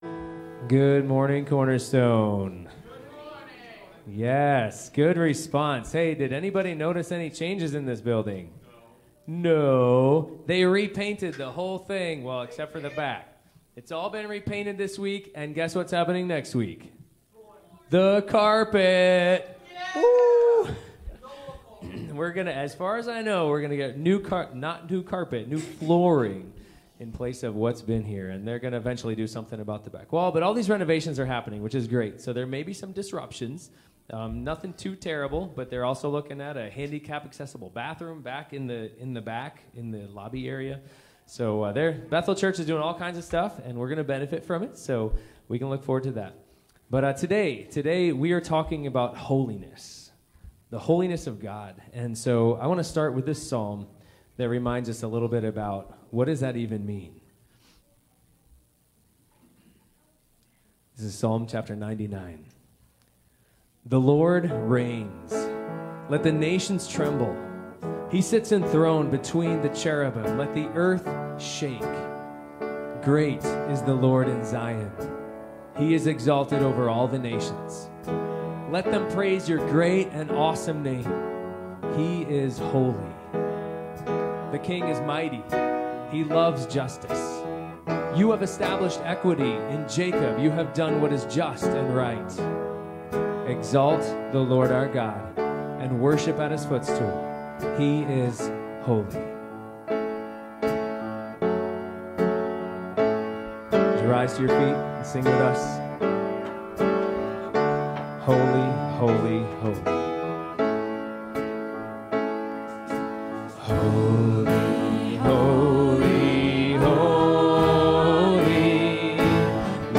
| Sermon Downloads
1 Timothy 6:11-16 Service Type: Sunday Morning Bible Text